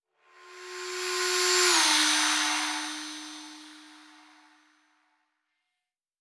Fly By 02_6.wav